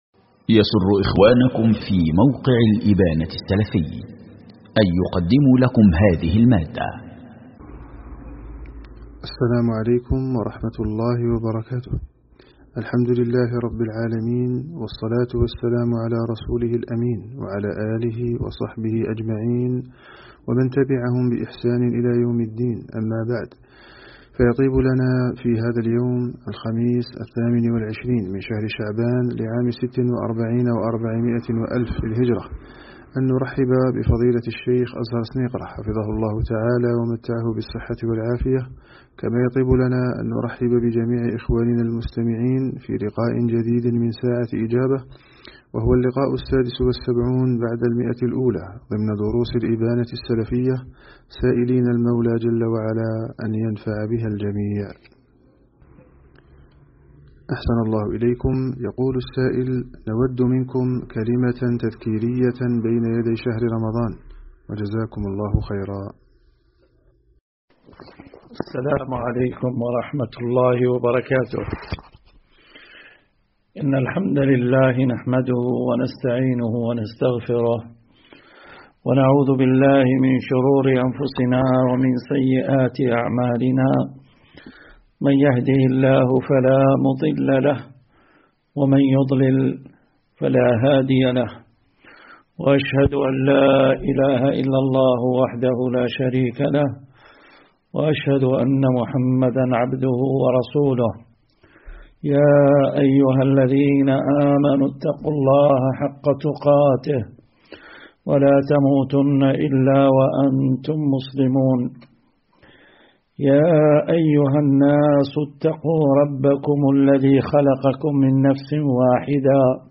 ساعة إجابة عن الأسئلة الواردة الدرس 176